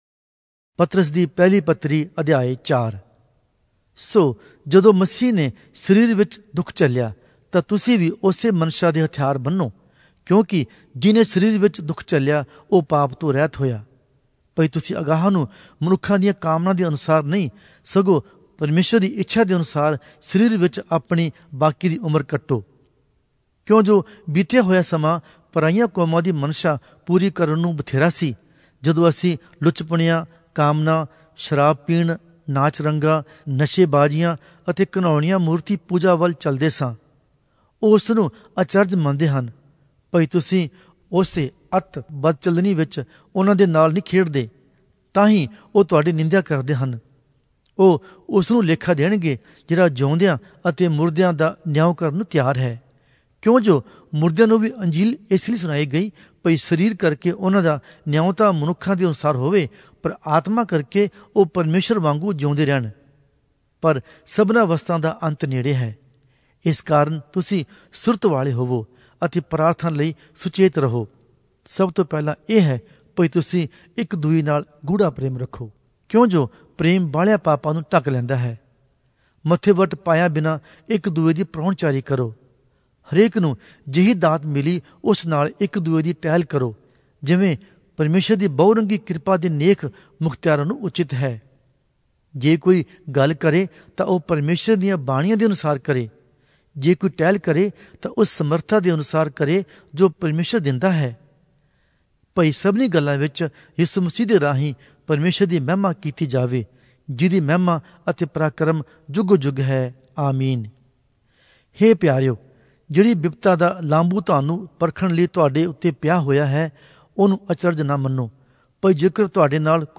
Punjabi Audio Bible - 1-Peter 2 in Gnterp bible version